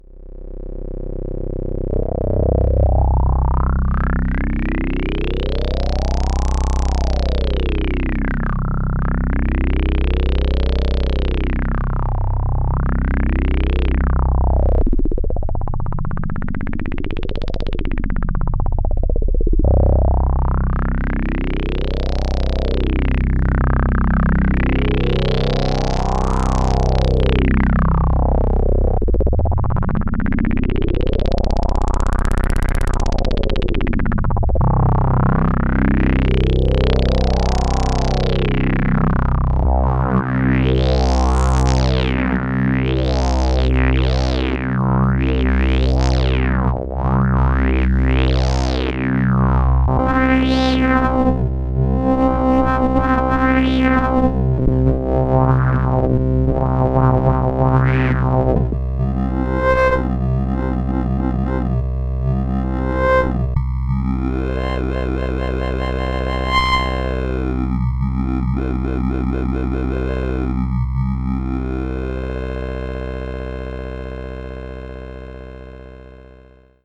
It’s just a quick and dirty try … on a single pitch … unprocessed
Maybe that gives an idea of what’s possible with filter-FM-modulation at audio rates …
thank you for this test, it’s very smooth.
My example was done on a Voyager.